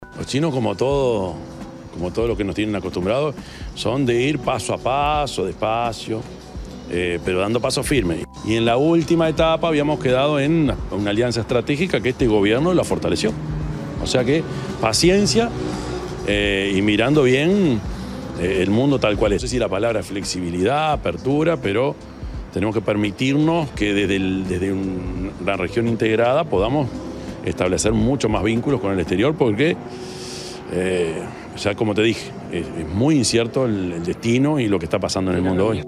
Orsi dijo en rueda de prensa: «La región es lo primero», luego de ser consultado por la reunión que mantuvo la vicepresidenta electa, con el embajador de China. Aseguró que «nadie está planteando» un TLC con China.